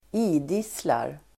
Ladda ner uttalet
Uttal: [²idis:lar]